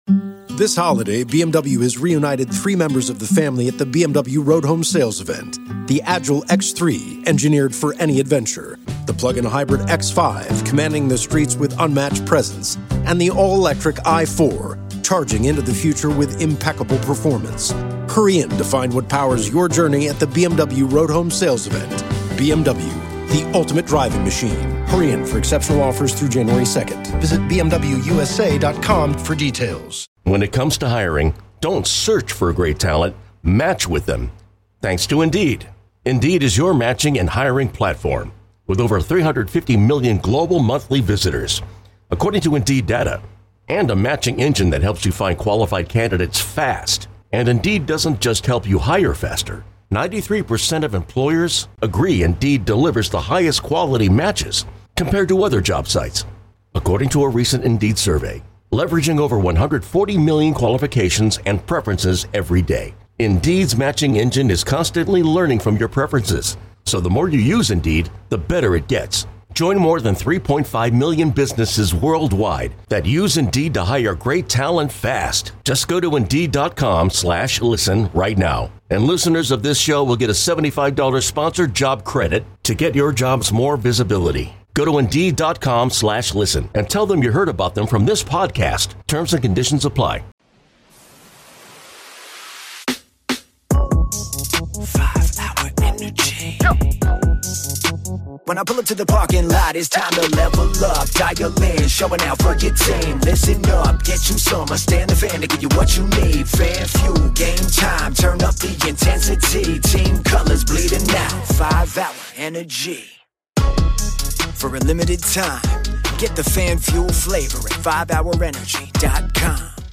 With all of the injuries the Steelers have, can they still keep it close against the Ravens this Saturday? One caller says Russell Wilson needs to step up this weekend otherwise he has no future with the Steelers, which everyone disagrees with.